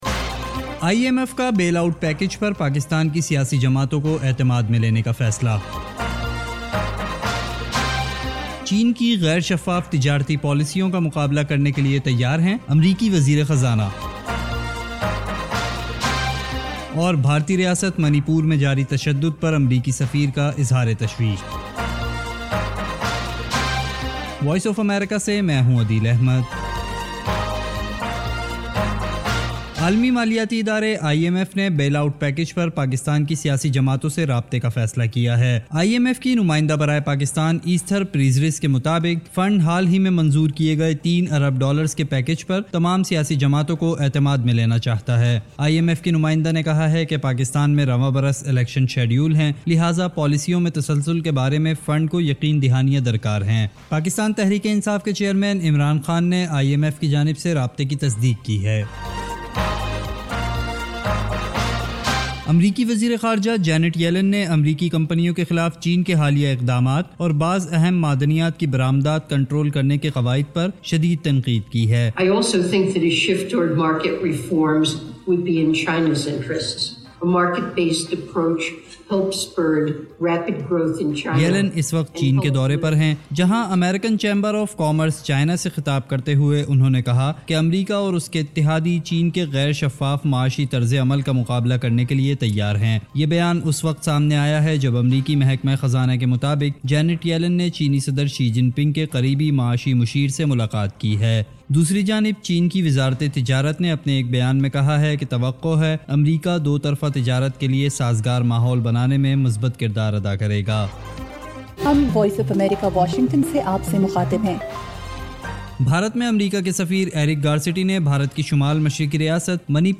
ایف ایم ریڈیو نیوز بلیٹن : شام 6 بجے